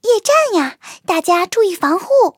卡尔臼炮夜战语音.OGG